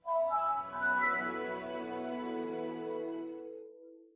MinderiaOS Pre-Beta Startup.wav